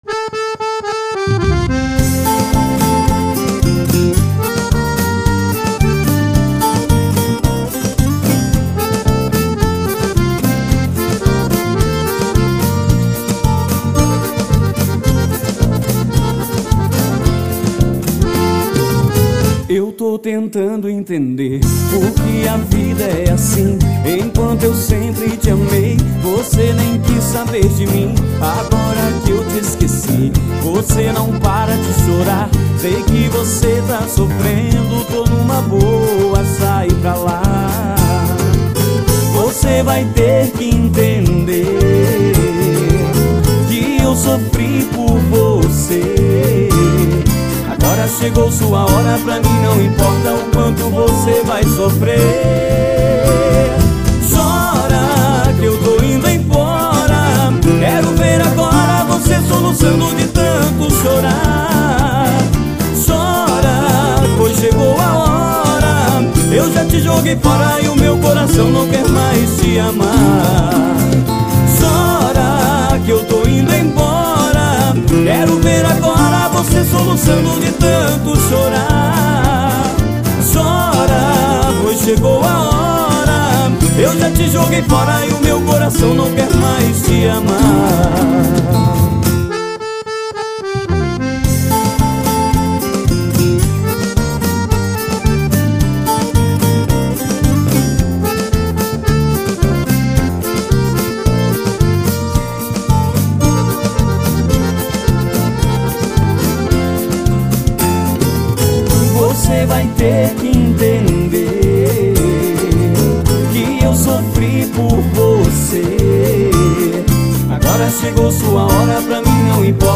EstiloCountry